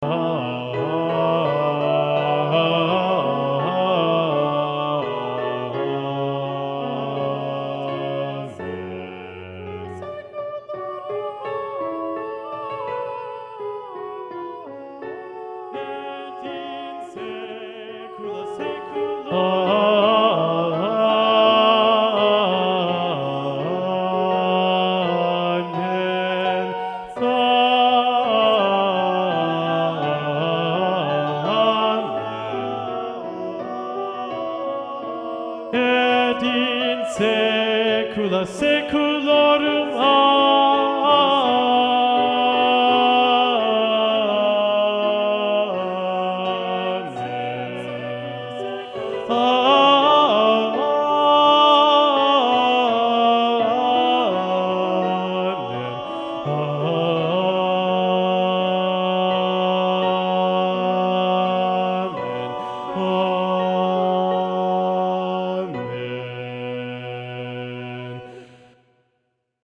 Bass learning track
domine_bass.mp3